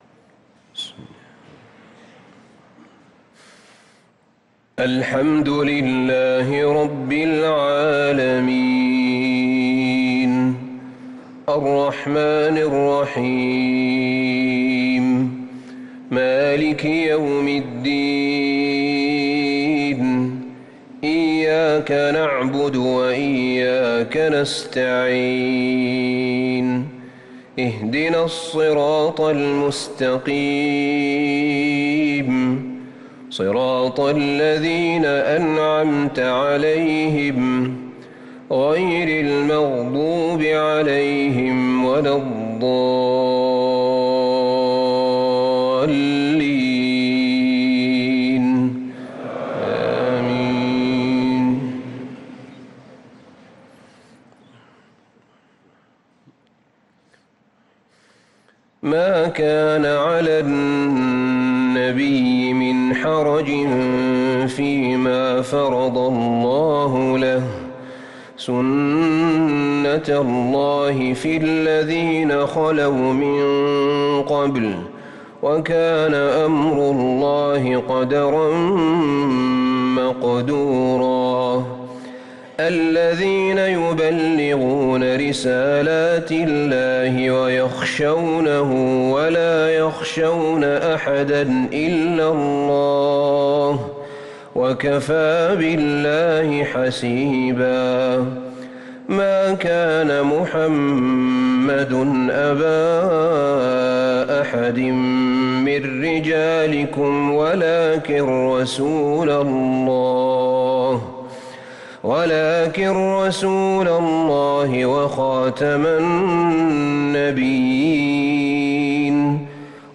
صلاة العشاء للقارئ أحمد بن طالب حميد 9 محرم 1445 هـ